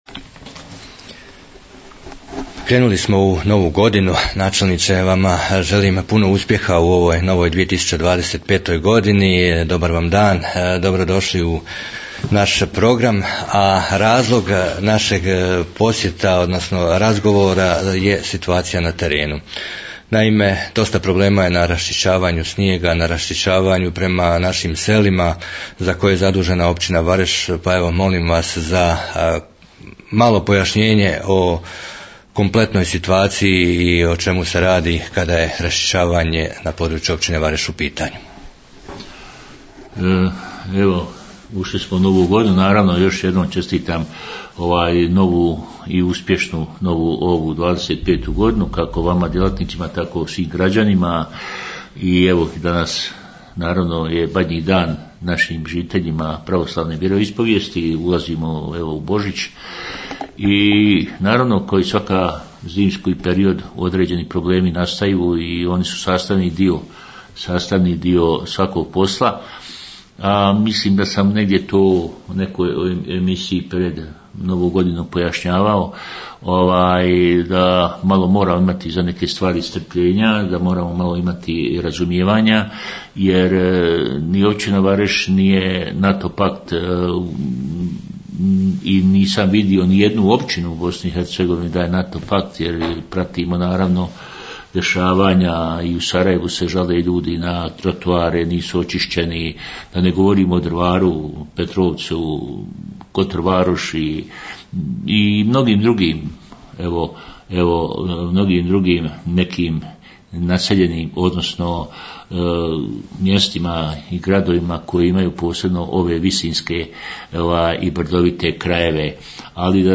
Raozgovor o zimskom održavanju puteva
Razgovarali smo s načelnikom Općine Vareš Zdravkom Maroševićem o zimskom održavanju puteva i problemu oko potpisivanja Ugovora s JKP Vareš iz kojeg smo dobili sljedeću informaciju.